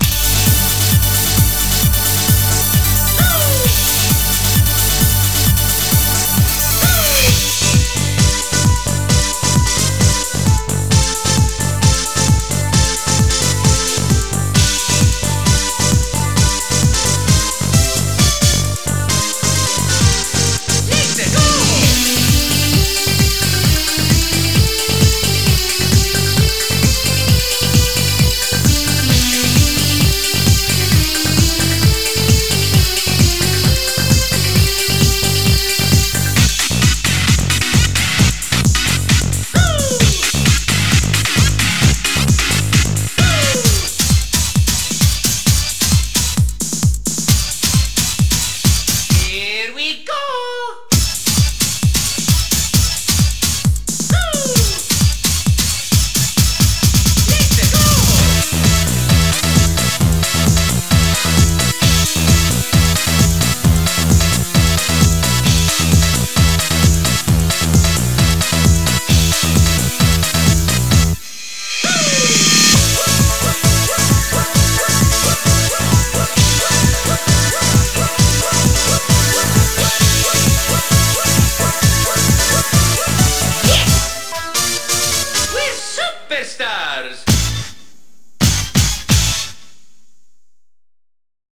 BPM132
Audio QualityPerfect (High Quality)
Better quality audio.